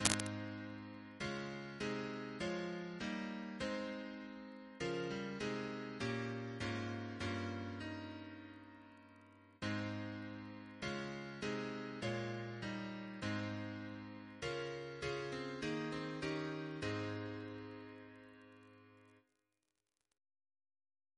Double chant in A♭ Composer: Rev Edmund S. Carter (1845-1923), Vicar Choral and Sub-Chanter of York Minster Reference psalters: ACB: 70